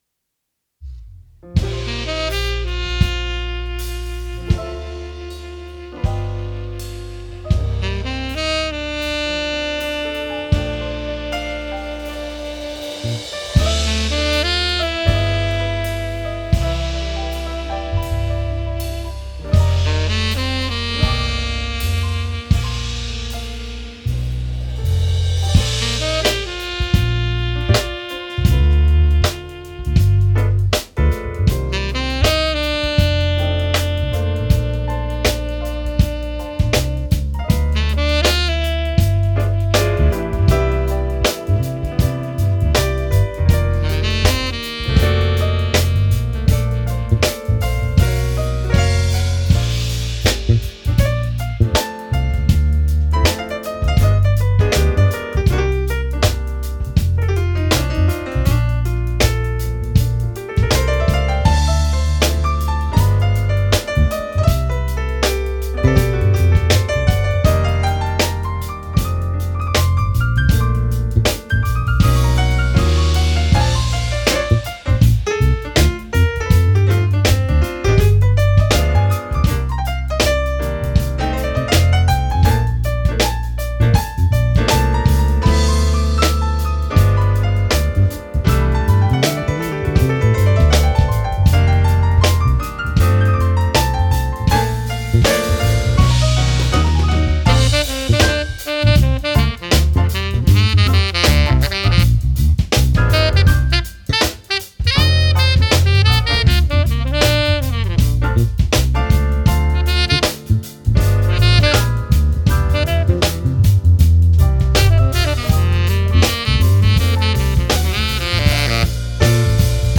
Tenor Saxophone
Keyboard
Bass
Drums